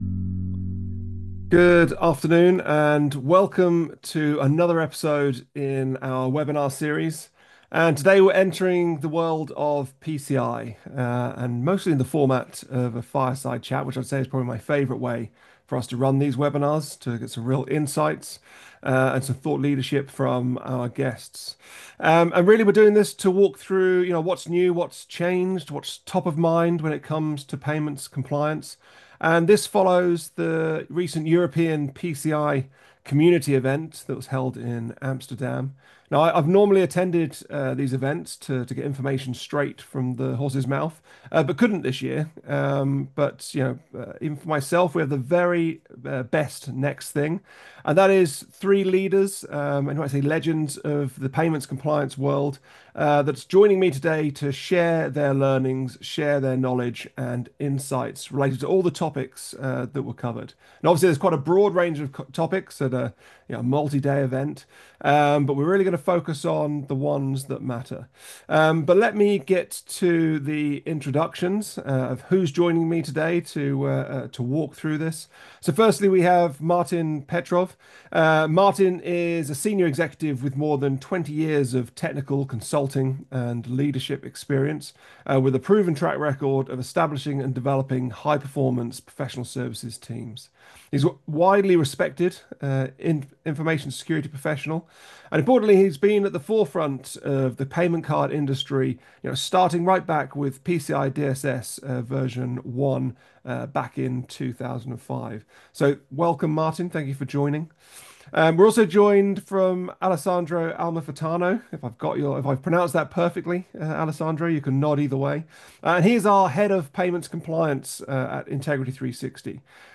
On demand webinar: PCI Amsterdam unpacked: Key takeaways, developments and insights from the latest PCI community event